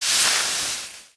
auto_after_fire.wav